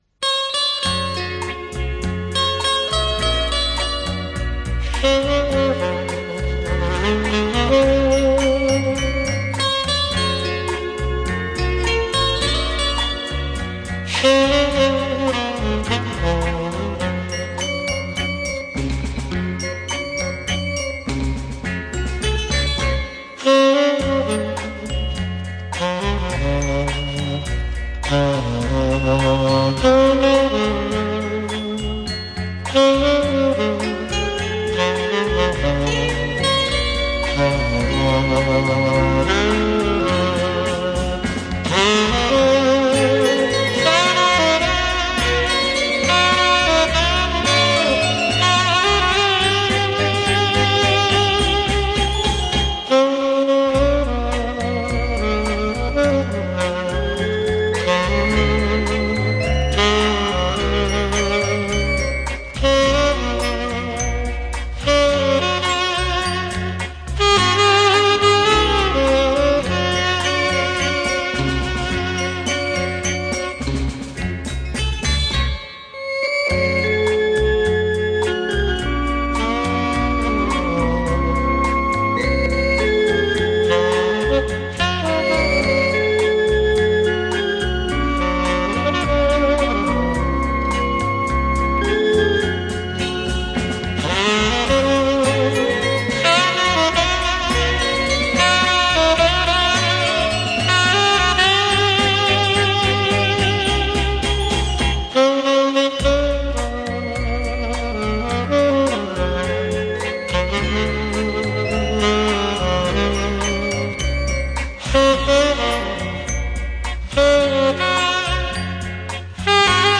Жанр: Easy Listening, Sax